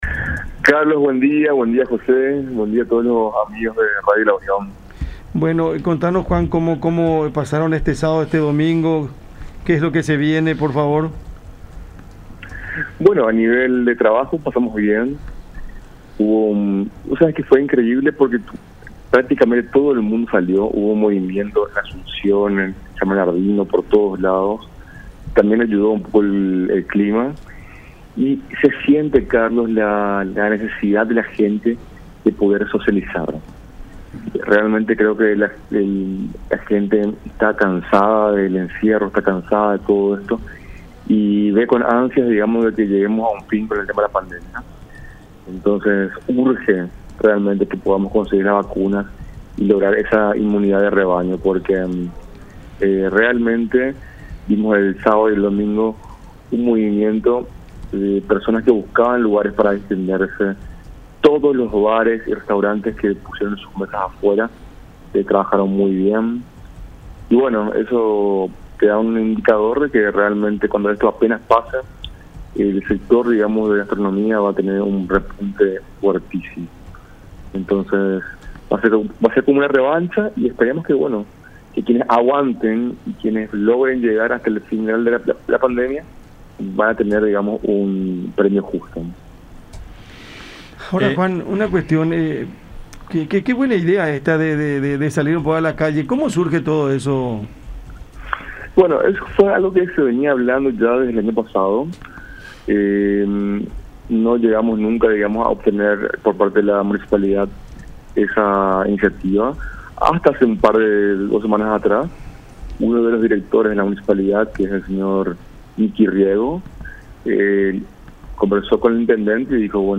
en conversación con el programa Cada Mañana a través de La Unión